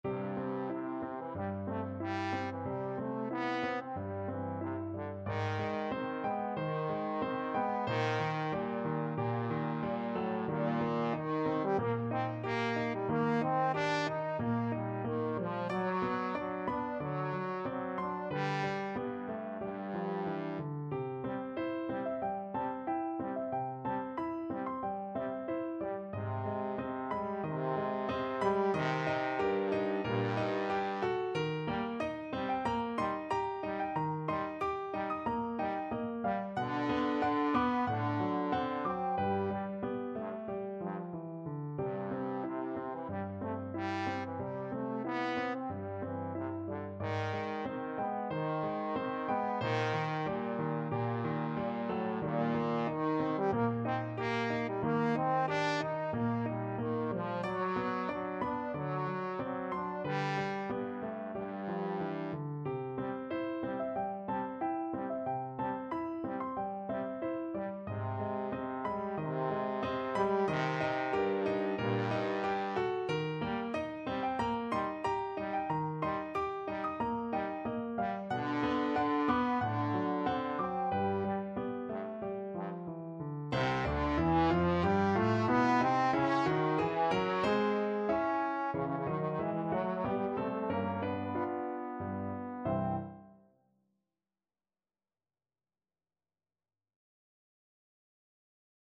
Andantino =92 (View more music marked Andantino)
G3-Eb5
Classical (View more Classical Trombone Music)